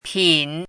chinese-voice - 汉字语音库
pin3.mp3